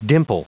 Prononciation du mot dimple en anglais (fichier audio)
Prononciation du mot : dimple